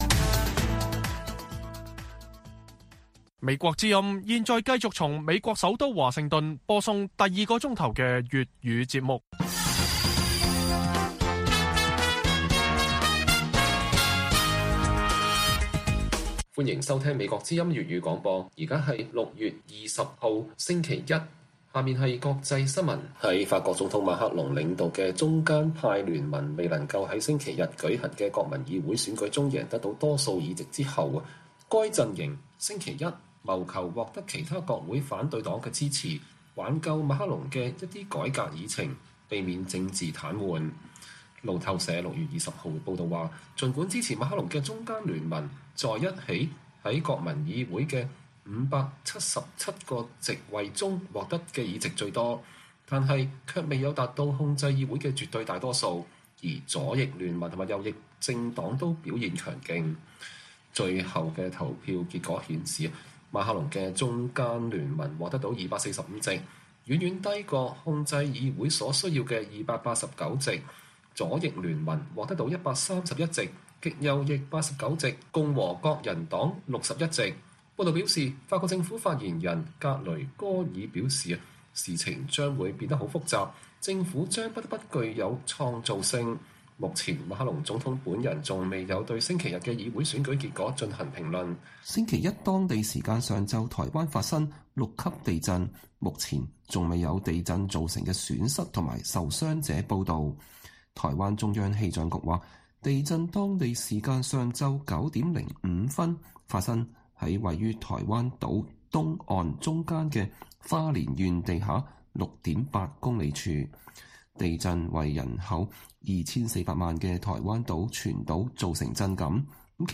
粵語新聞 晚上10-11點: 法國總統馬克龍的陣營在國會選舉遭遇重挫